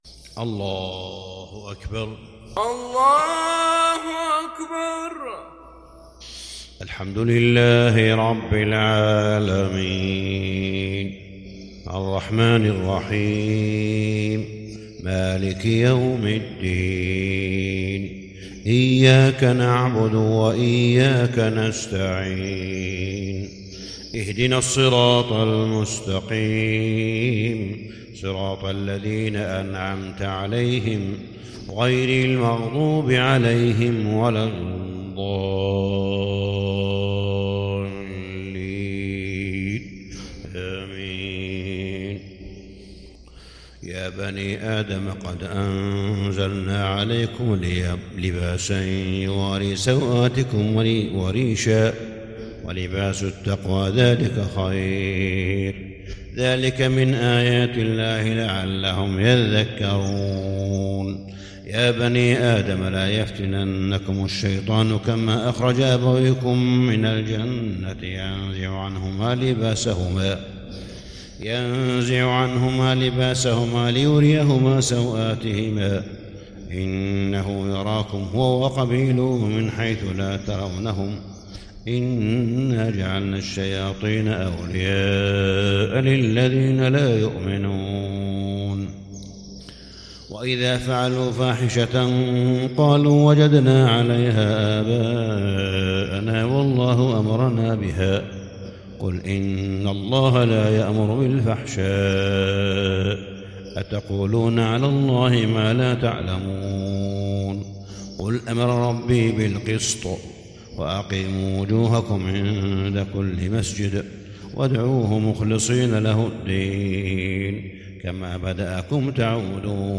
صلاة الفجر للشيخ صالح بن حميد 18 رجب 1441 هـ
تِلَاوَات الْحَرَمَيْن .